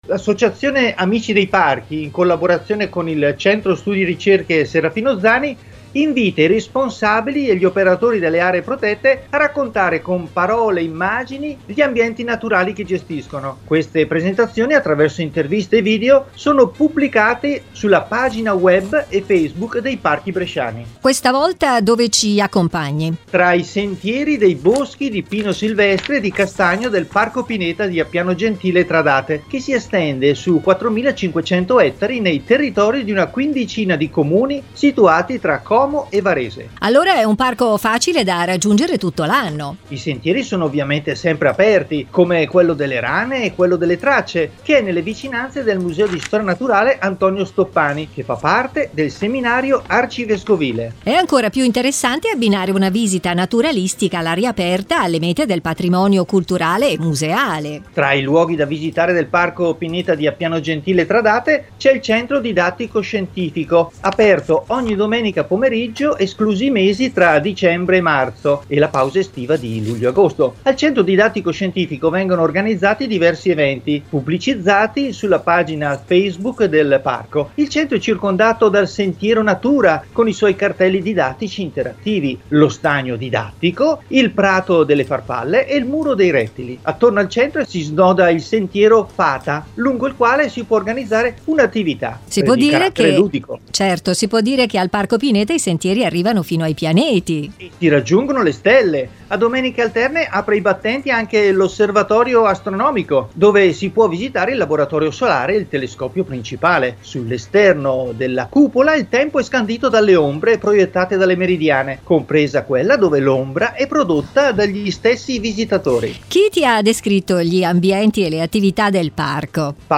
“Trasmissione sul Parco Pineta di Appiano Gentile-Tradate (Co) andata in onda su Radio Brescia Sette in data 04.10.2020. Ecco